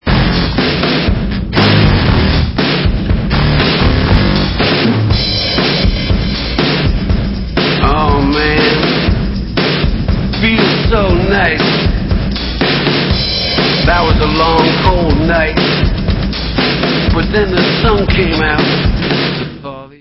americká indie-rocková kapela